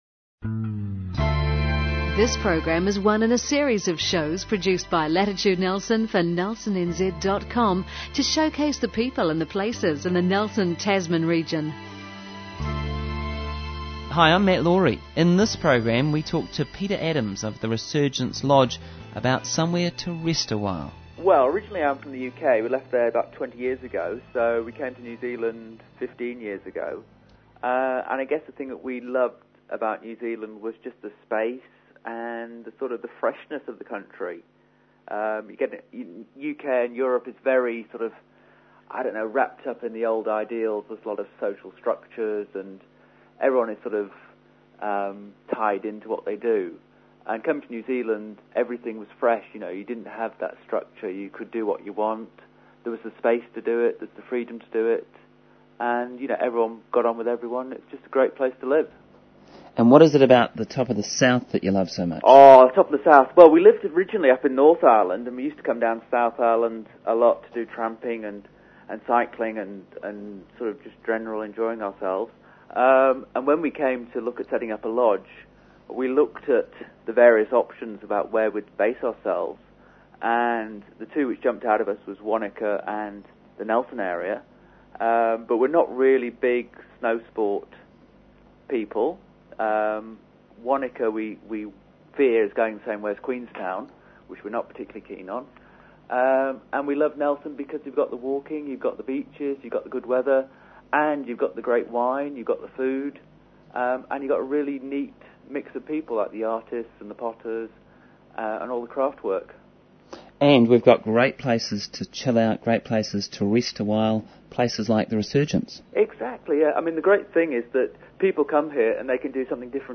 Interview
resurgence interview.mp3